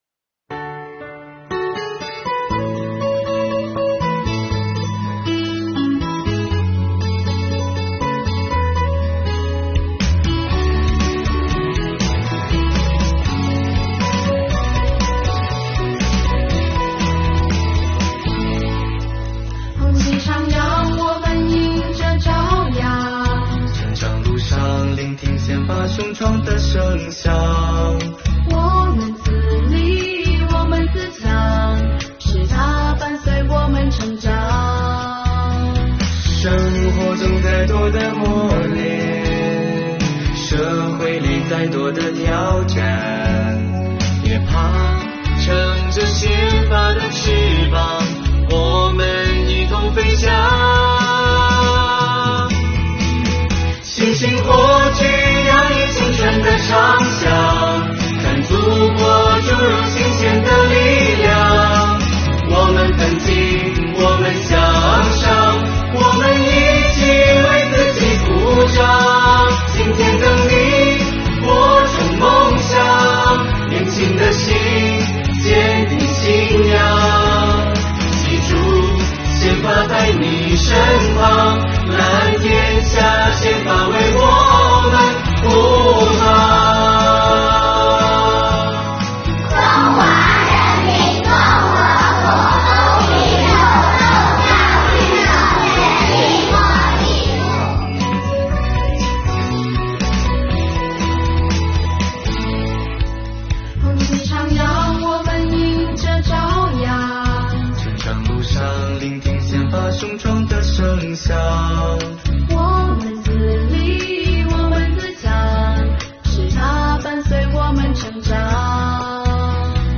市委依法治市办、市司法局、市教育局、市检察院、梧州市税务局、梧州供电局、市律师协会联合推出《宪法伴我成长》宪法主题MV宣传片，主题宣传片中，我市各中小学校以青春昂扬的歌声向宪法致敬，展现出我市青少年的蓬勃朝气、昂扬锐气的青春气息，坚定不移做宪法的忠实崇尚者、忠诚维护者和积极践行者。
宪法主题MV《宪法伴我成长》